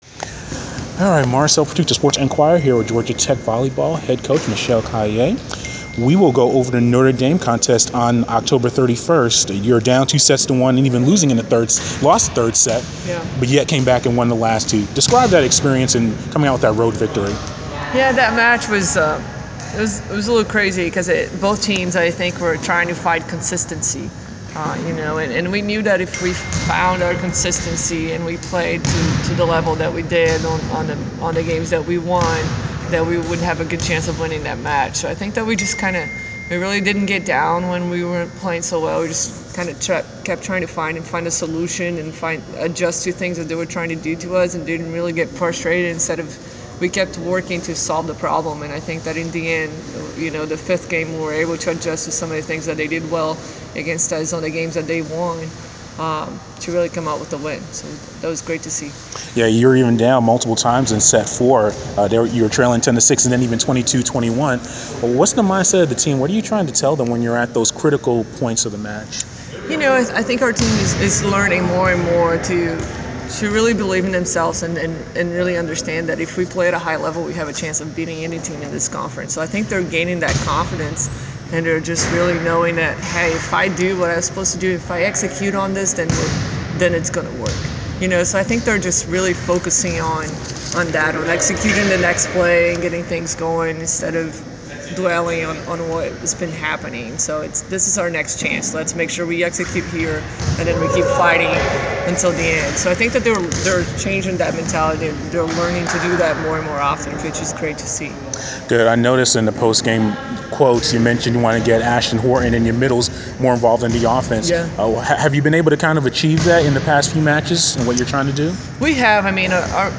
Interview
after practice on Nov. 3